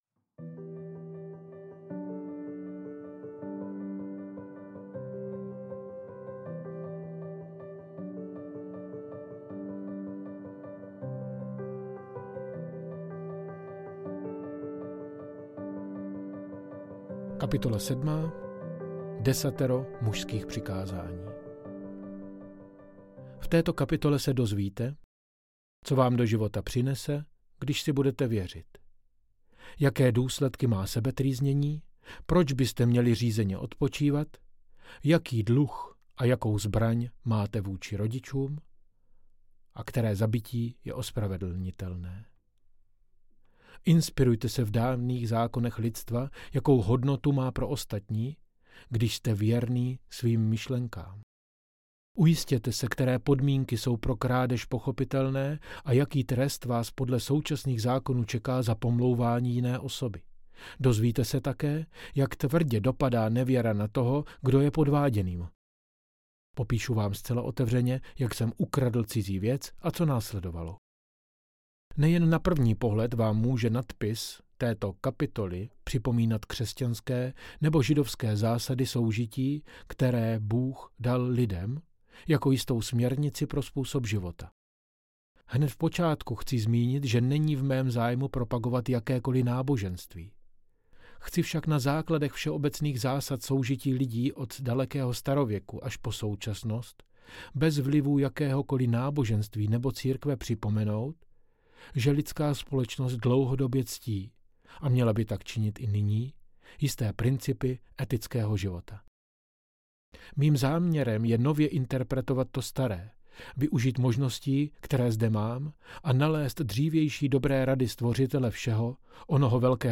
Gentlemanual audiokniha
Ukázka z knihy